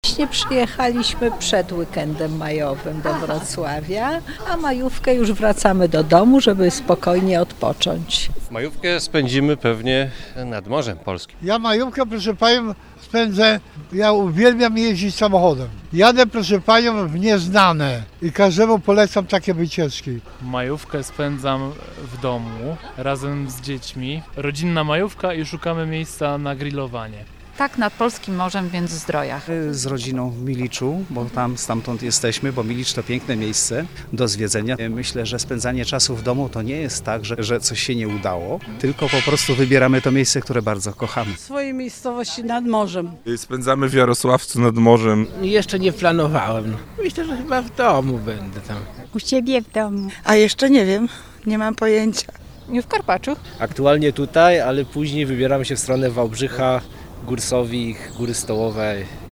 Pytamy Dolnoślązaków, gdzie spędzą ten czas.
sonda-majowka.mp3